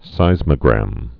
(sīzmə-grăm)